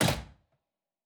Fantasy Interface Sounds
UI Tight 19.wav